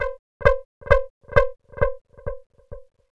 Index of /90_sSampleCDs/USB Soundscan vol.51 - House Side Of 2 Step [AKAI] 1CD/Partition D/02-FX LOOPS
STNOISE05 -L.wav